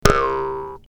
clock05.ogg